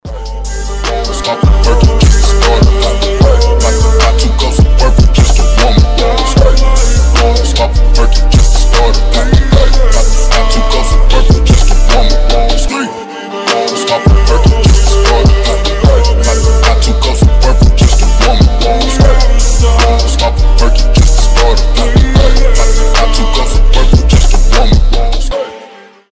• Качество: 320, Stereo
гитара
deep house
красивая мелодия